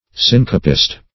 Syncopist \Syn"co*pist\, n. One who syncopates.